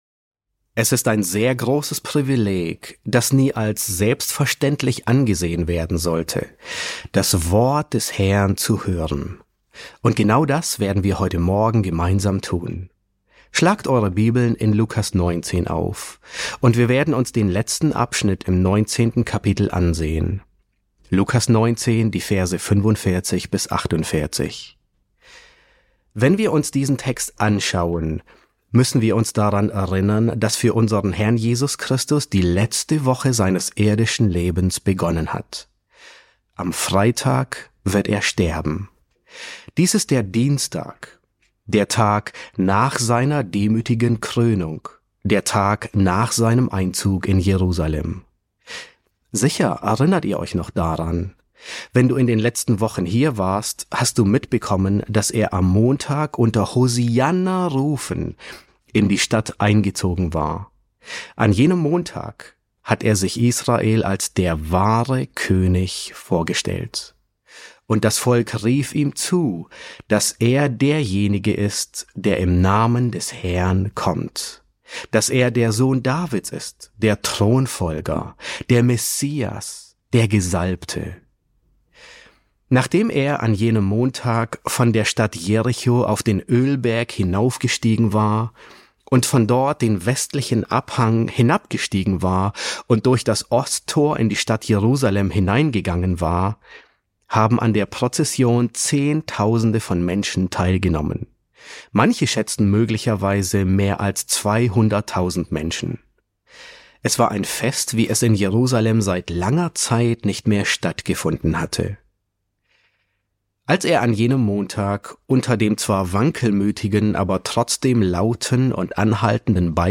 Predigten auf Deutsch